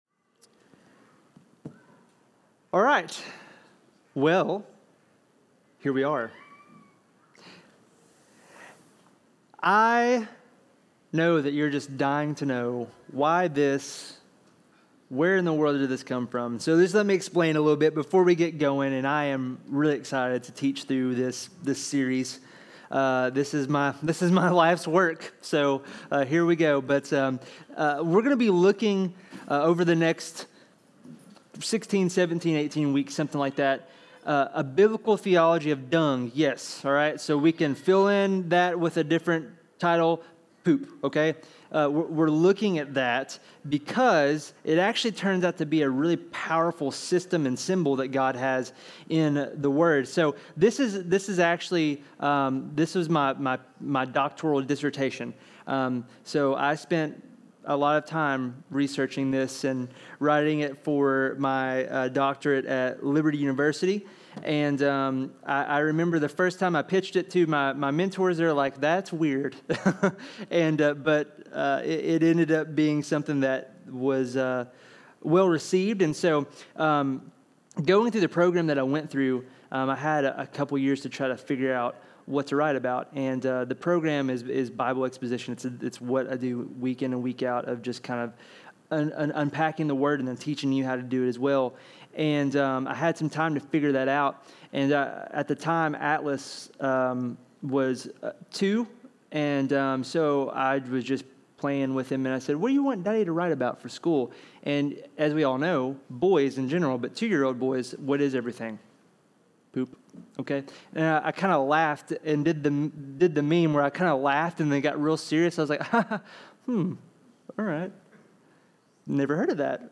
Sermons | Silver City Church